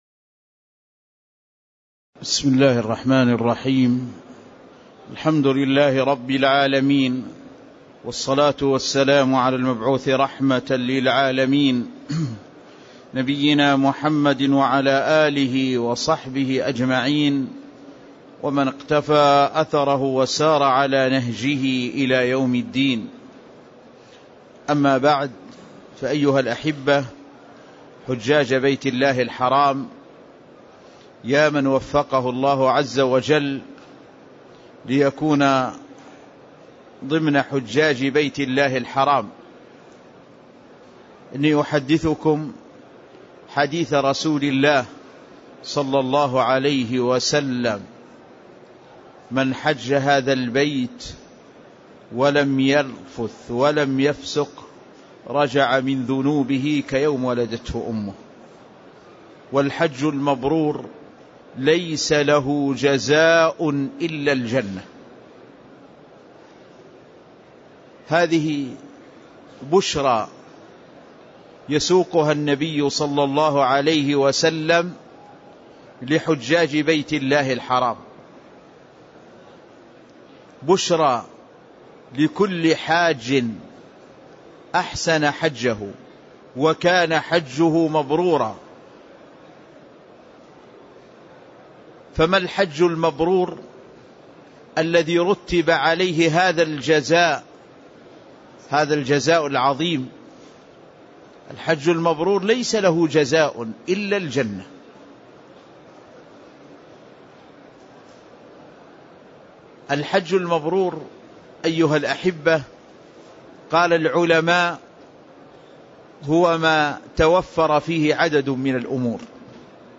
تاريخ النشر ١ ذو الحجة ١٤٣٥ هـ المكان: المسجد النبوي الشيخ